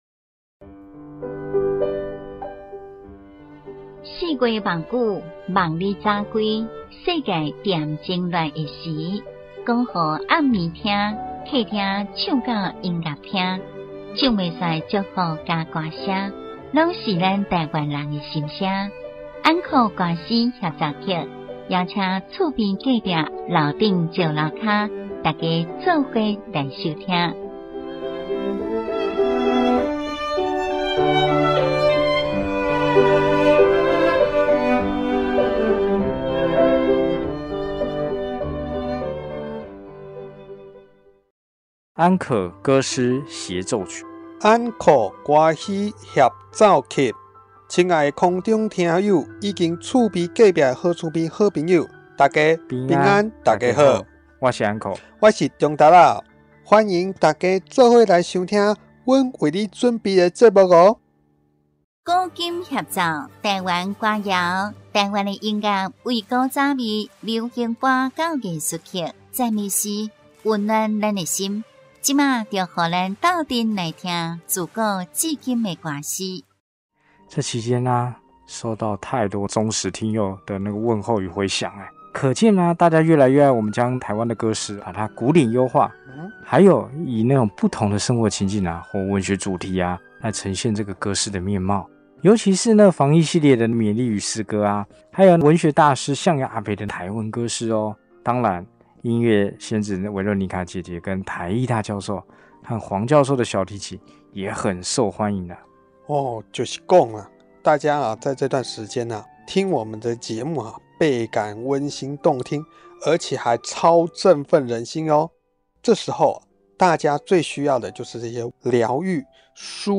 2.其他合唱或流行歌：由國內最知名合唱團體或名歌手等分享。